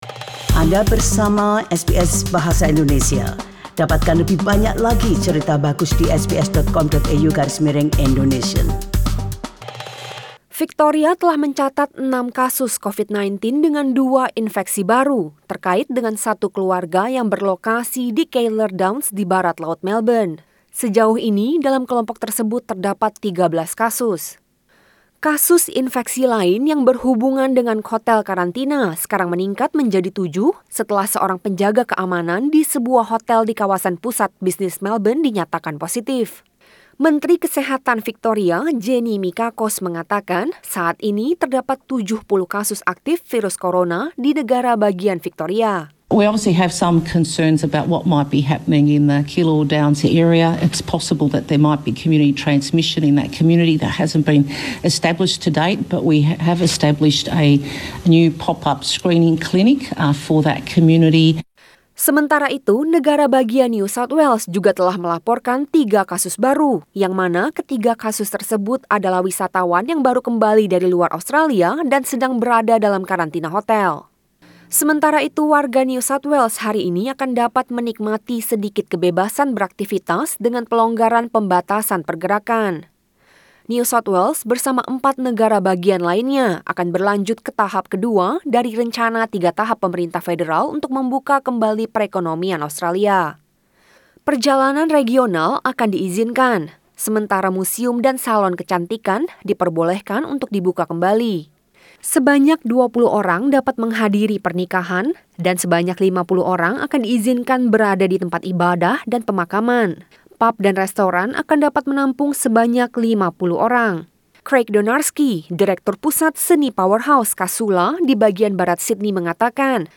News bulletin in Indonesian 1 June 2020